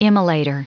Prononciation du mot immolator en anglais (fichier audio)
Prononciation du mot : immolator